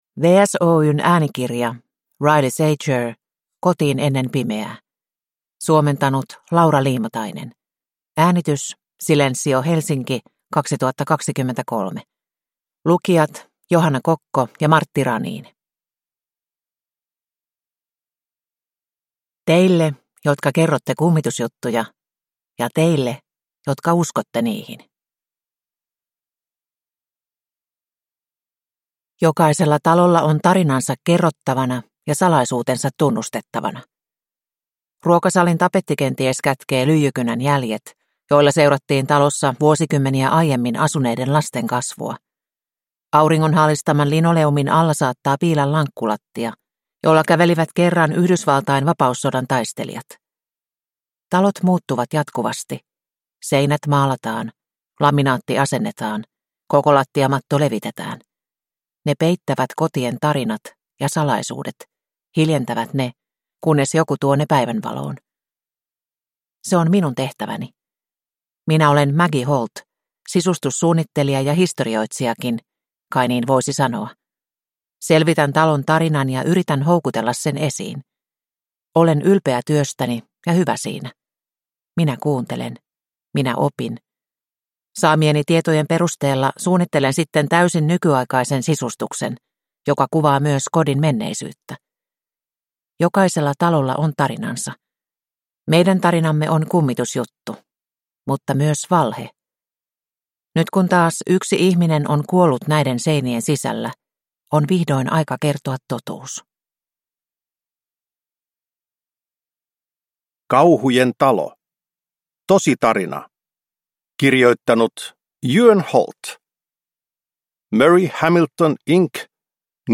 Kotiin ennen pimeää (ljudbok) av Riley Sager